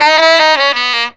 COOL SAX 9.wav